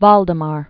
(väldə-mär) or Wal·de·mar I (wôl-, väl-) Known as "Valdemar the Great." 1131-1182.